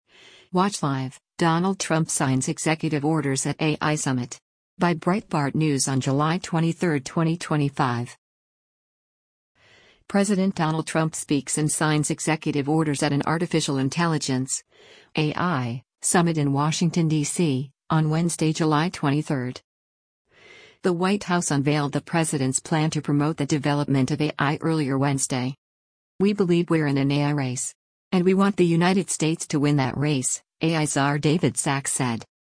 President Donald Trump speaks and signs executive orders at an artificial intelligence (AI) summit in Washington, DC, on Wednesday, July 23.